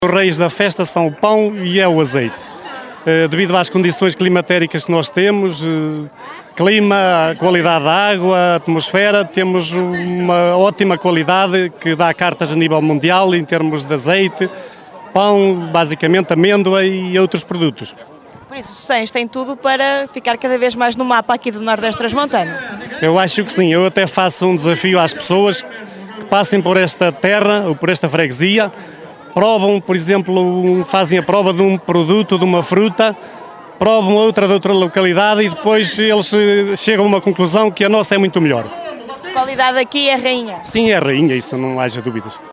E se eles são os reis, a qualidade é rainha, como nos conta o presidente da junta de freguesia, Rui Fernandes.